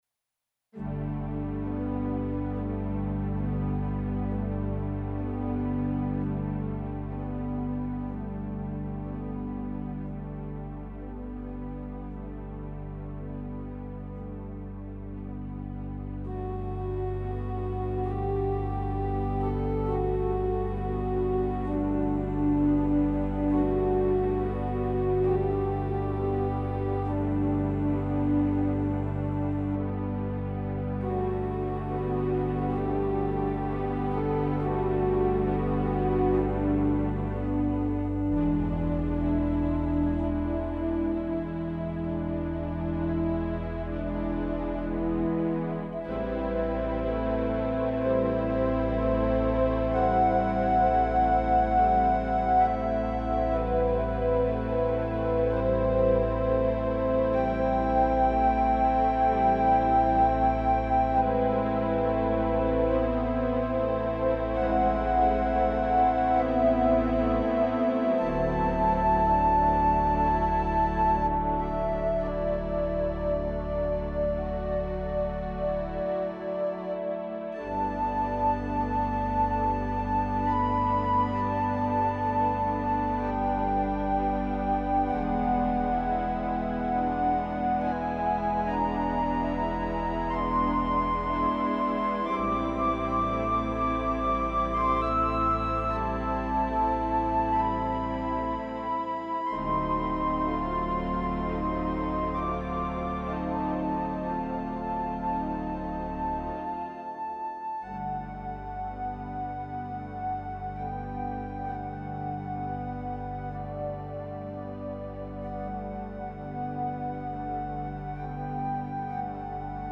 Organ Meditations Audio Gallery
Peaceful works for quiet reflection